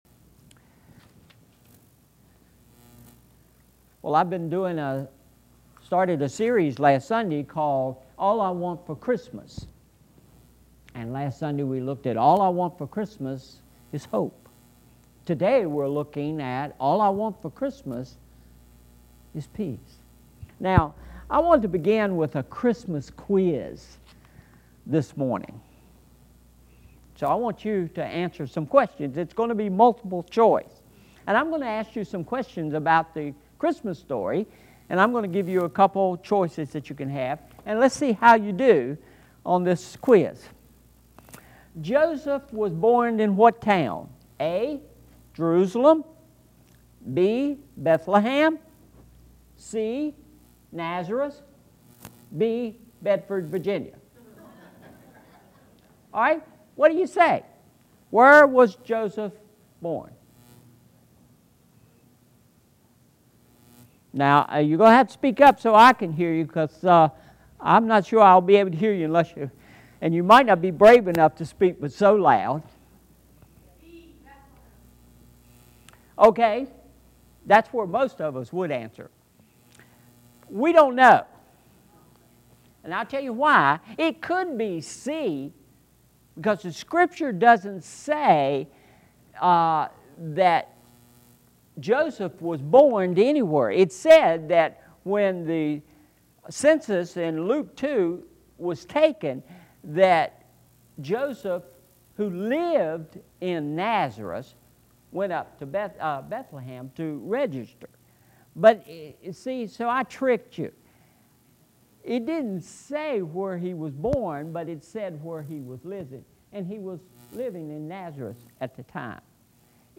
Sermon Series: All I Want for Christmas – Part 2